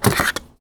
R - Foley 229.wav